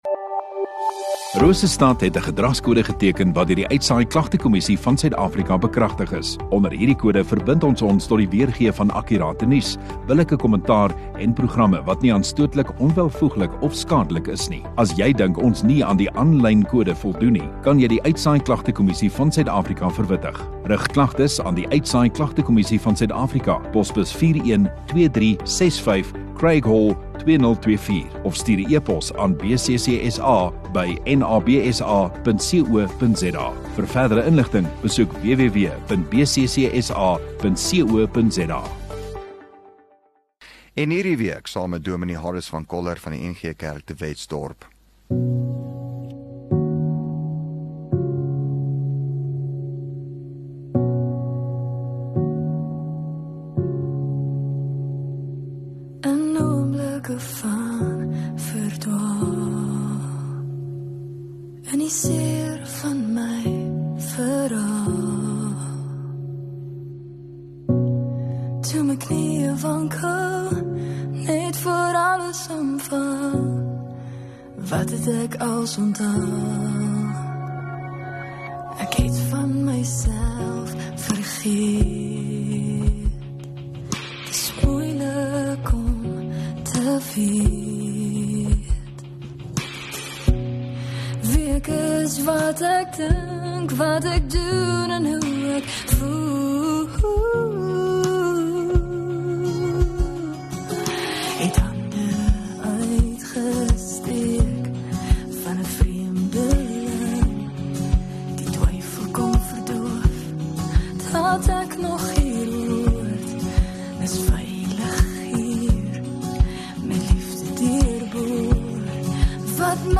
2 Jul Woensdag Oggenddiens